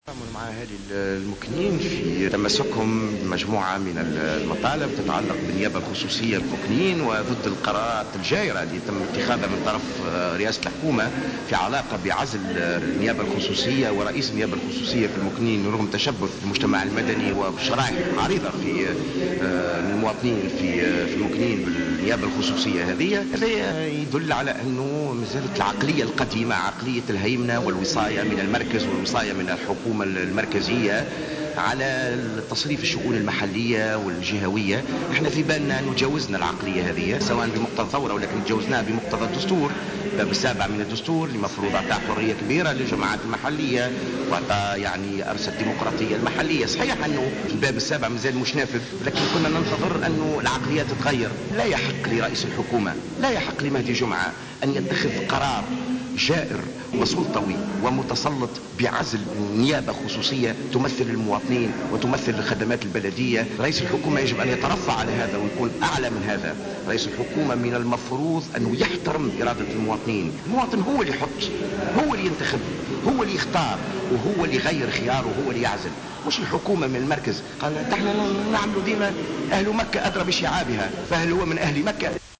نظمت اليوم جمعية منتدى الرابع عشر من جانفي للمرأة محاضرة في المكنين بعنوان الديمقراطية التشاركية أمّنها رئيس شبكة دستورنا جوهر بن مبارك الذي اعتبر قرار عزل رئيس النيابة الخصوصية لبلدية المكنين بالقرار الجائر.